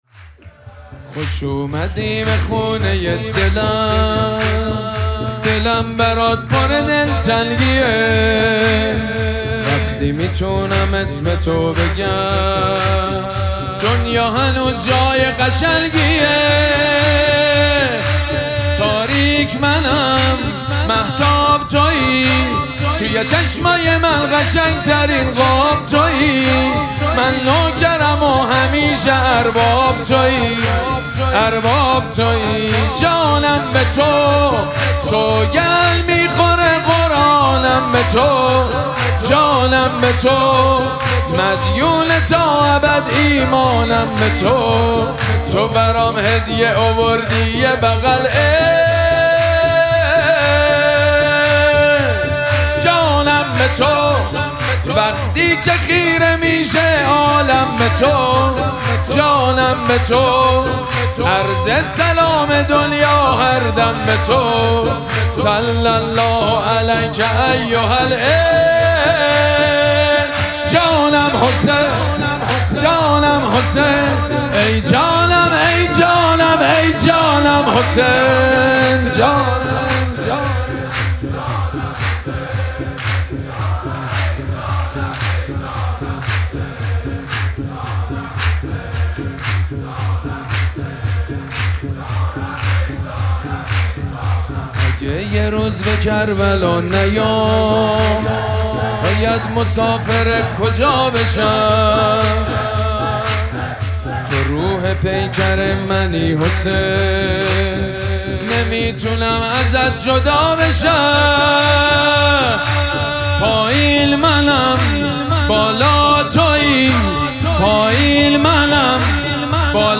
شب دوم مراسم جشن ولادت سرداران کربلا
حسینیه ریحانه الحسین سلام الله علیها
سرود
مداح
حاج سید مجید بنی فاطمه